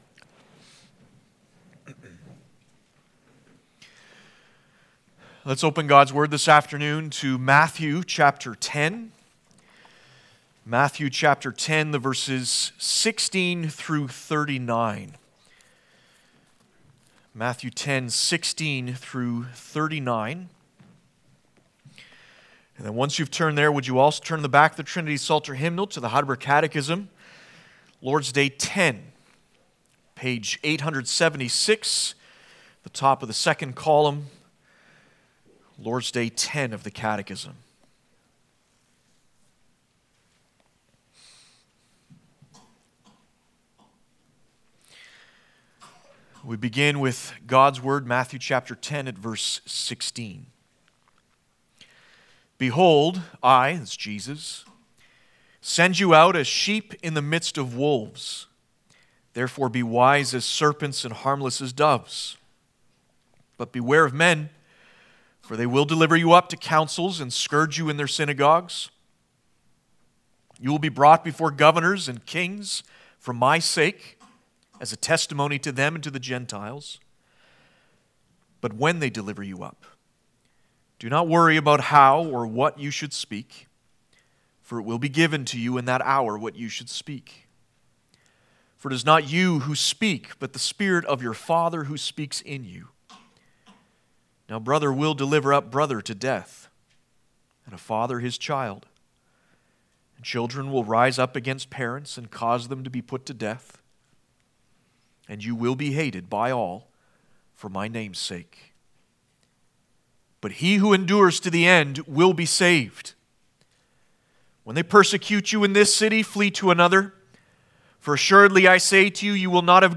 Passage: Matt 10:16-39 Service Type: Sunday Afternoon